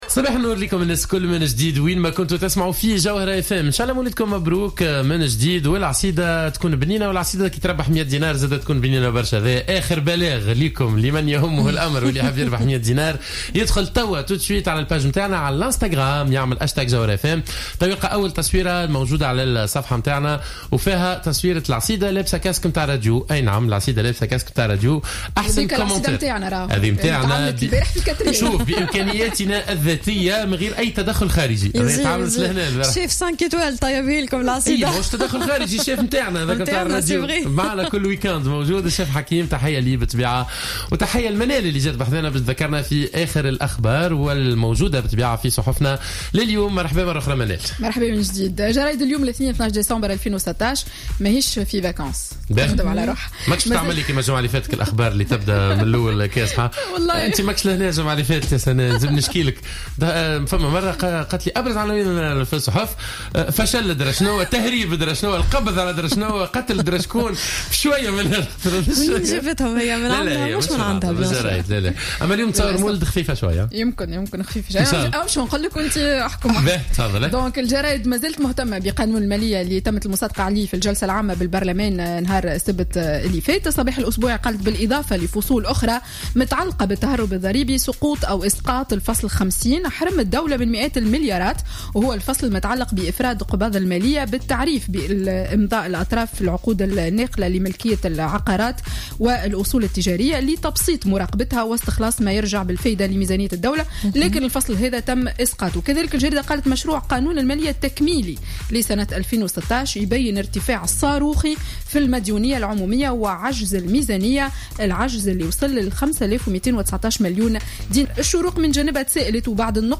Revue de presse du lundi 12 Décembre 2016